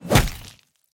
sfx_axe_body_hit.mp3